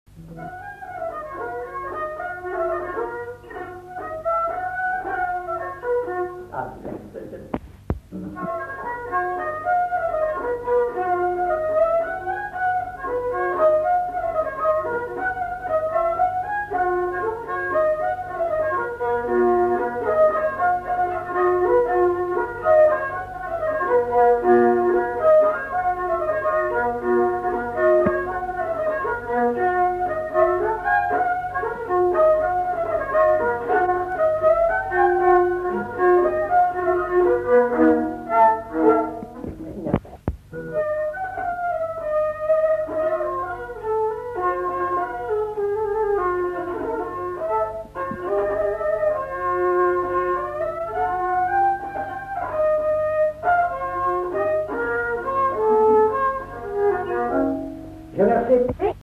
Lieu : Haut-Mauco
Genre : morceau instrumental
Instrument de musique : violon
Danse : congo
Notes consultables : Suivi d'un fragment de mélodie.